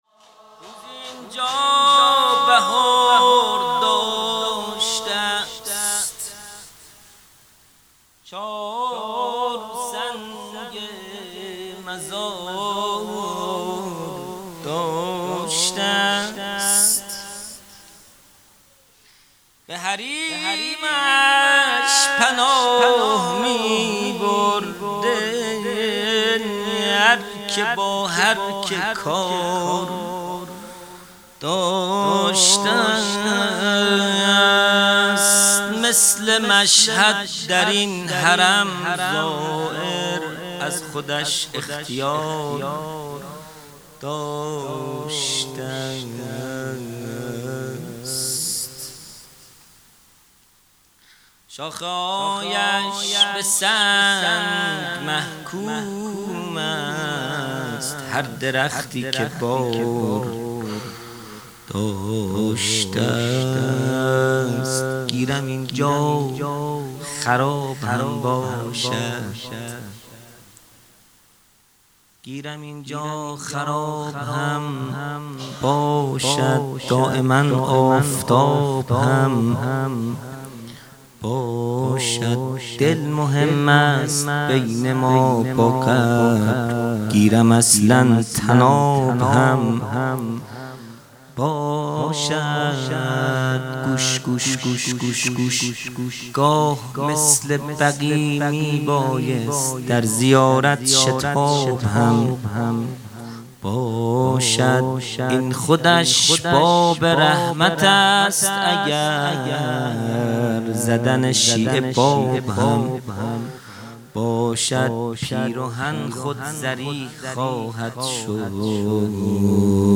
مناجات پایانی | روزی اینجا بهار داشته است | 22 اردیبهشت 1401
جلسۀ هفتگی | سالروز تخریب بقیع | پنجشنبه 22 اردیبهشت 1401